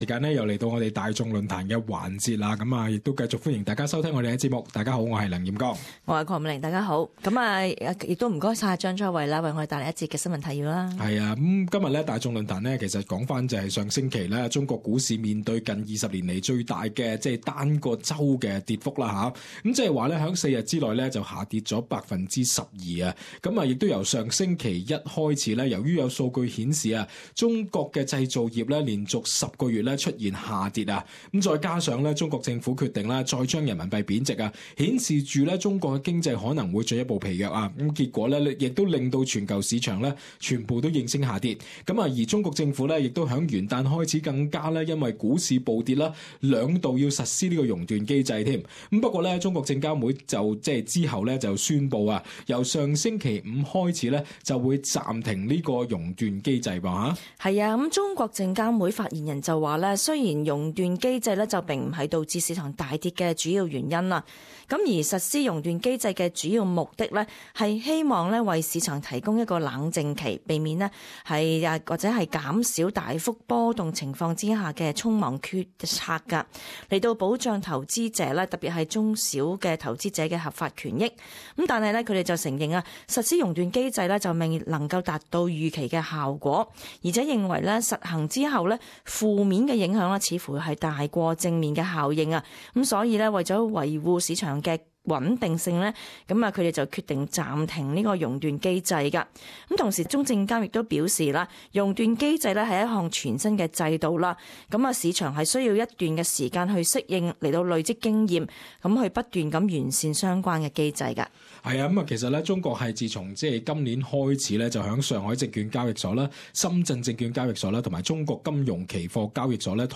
Talkback - Should China stop the trading curb measure?